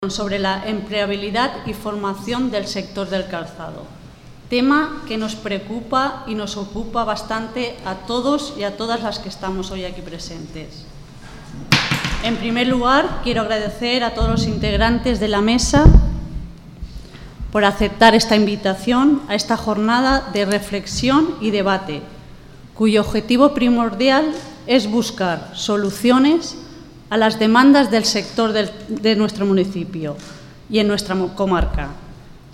Interesante charla debate sobre empleabilidad y formación del sector del calzado
El acto lo ha iniciado el alcalde de Pinoso, Lázaro Azorín, quien ha agradecido la presencia de las personas asistentes, esperando que el día de hoy “sea un punto de inflexión en todo aquello que cada sector trabaja para mejorar y  conocer el sector del calzado”.